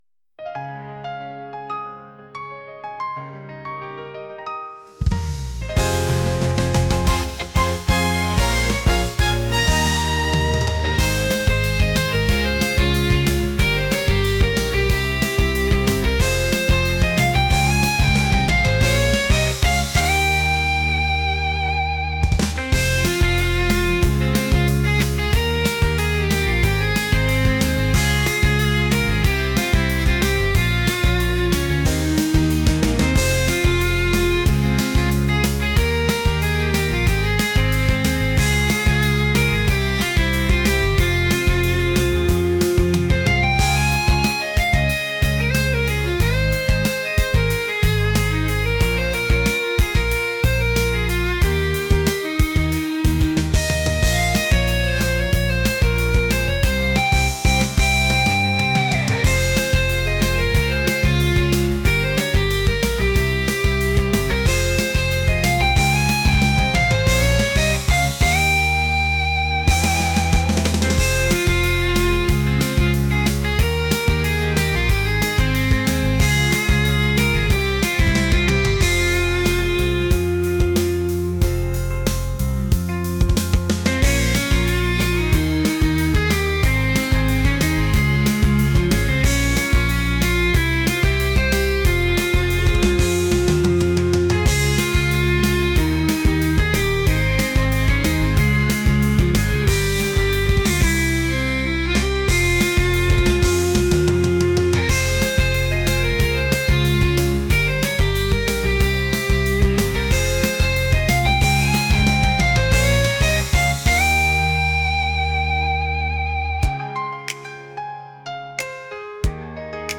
pop | energetic